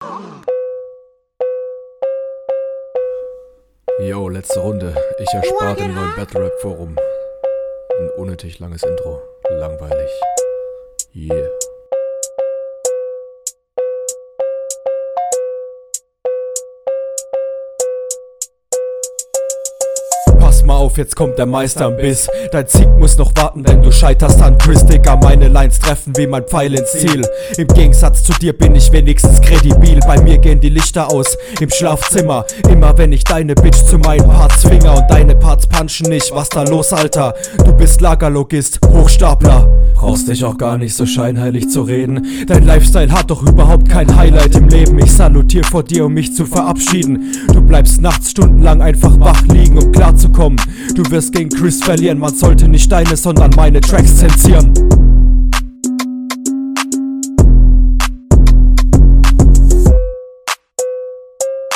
Diesmal kommt deine Stimme deutlich besser gemischt.
Diesmal war es Klanglich Cooler auch wenn Credibil offbeat war.